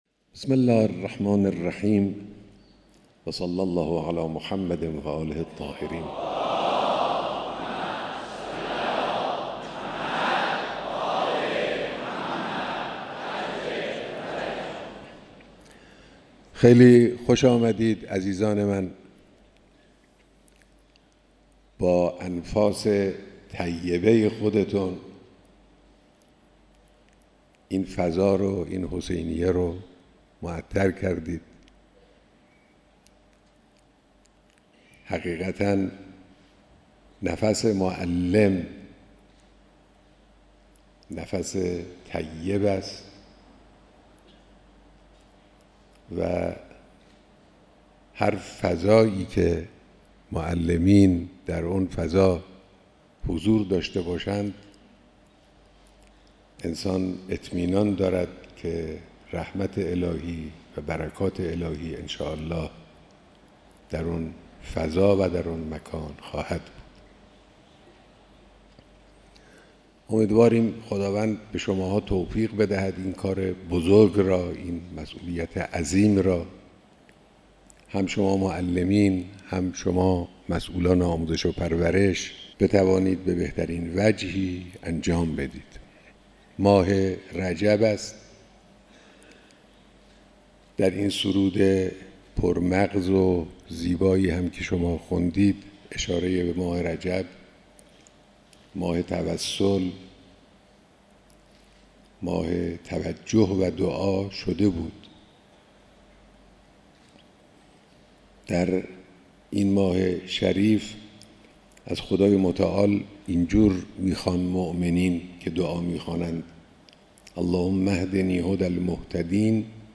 بیانات در دیدار هزاران نفر از معلمان سراسر کشور